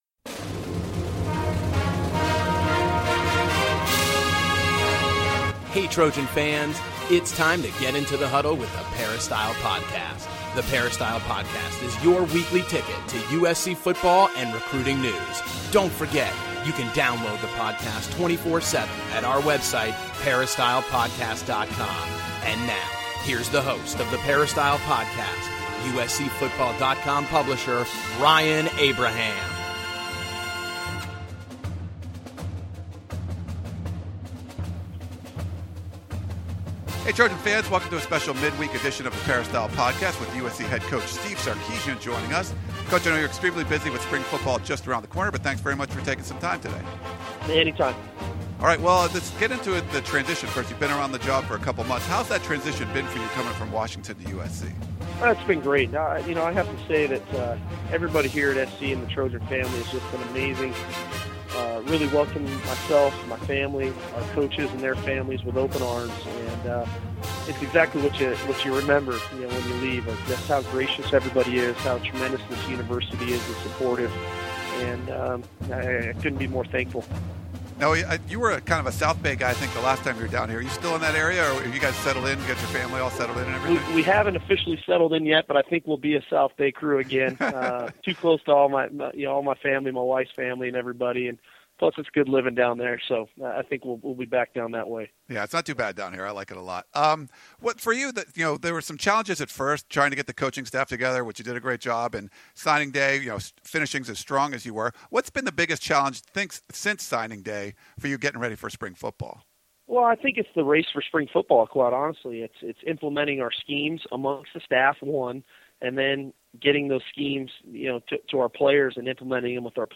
USC head coach Steve Sarkisian joins the Peristyle Podcast to talk about how the transition has been for him and give us a preview of spring football for the Trojans.